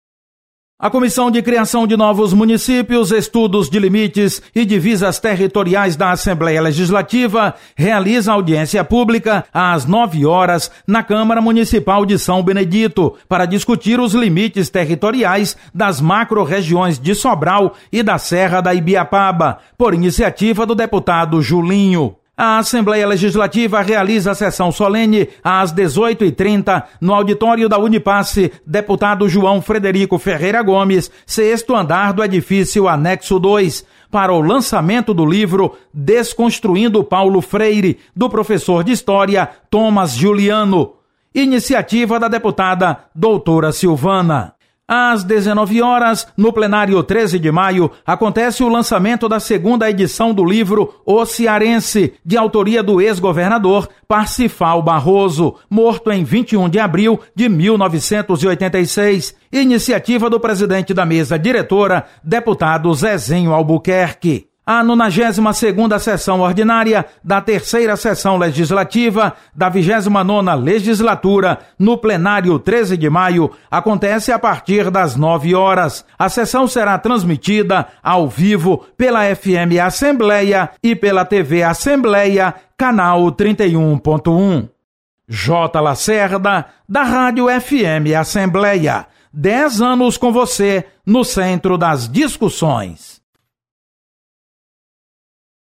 Acompanhe as atividades desta terça-feira na Assembleia Legislativa com o repórter